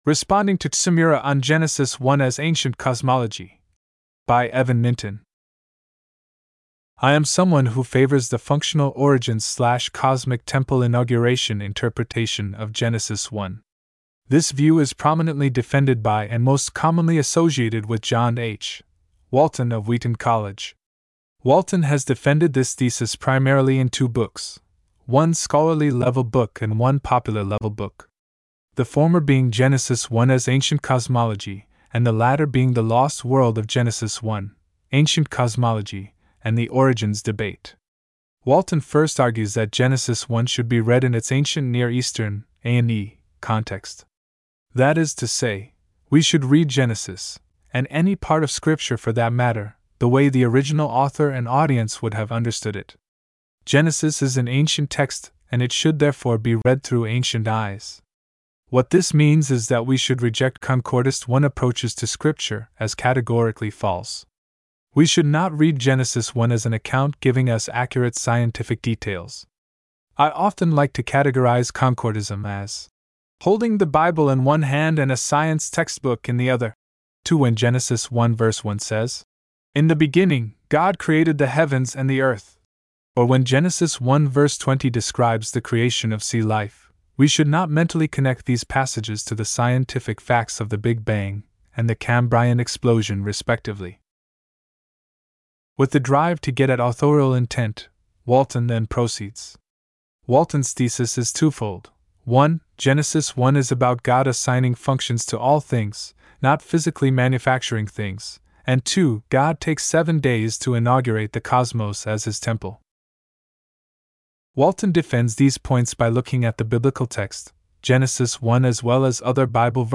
Audio accessibility for this blog post is powered by Microsoft Text-to-Speech technology.